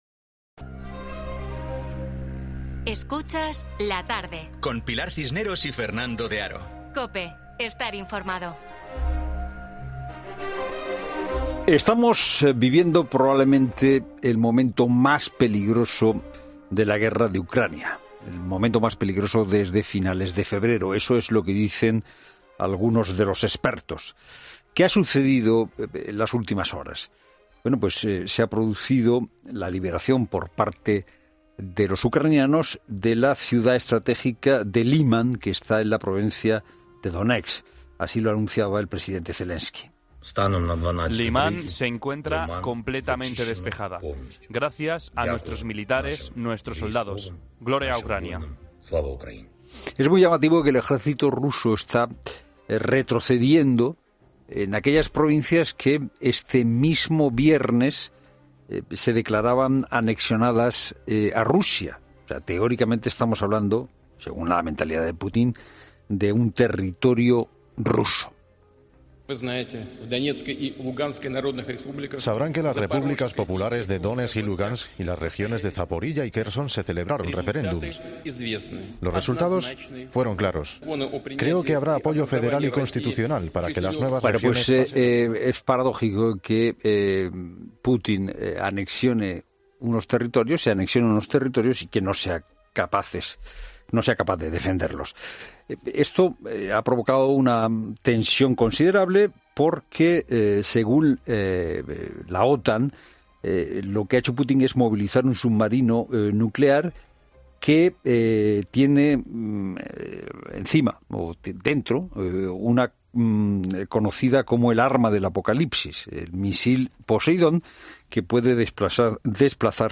Para poder explicar esta situación ha pasado por los micrófonos de 'La Tarde'